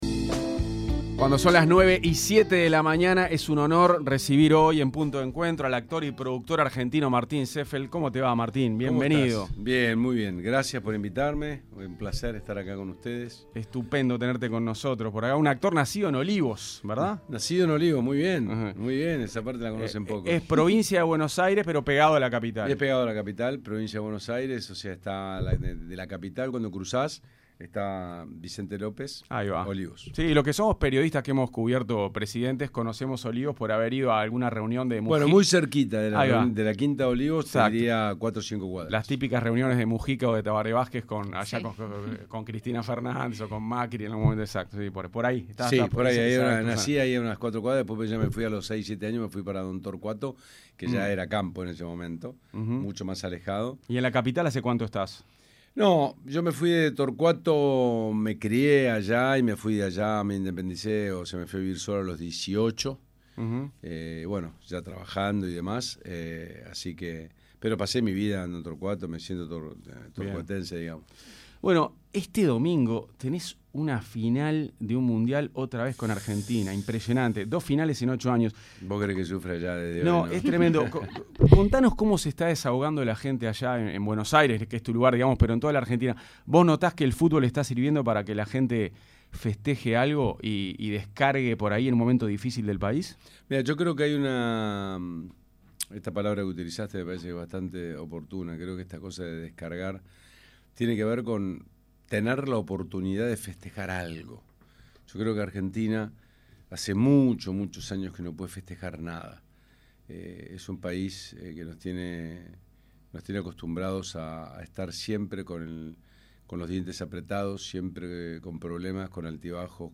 El actor argentino, Martín Seefeld, fue entrevistado en Punto de Encuentro de 970 Universal y se refirió a la serie “Los Simuladores” a 20 años del primer capítulo.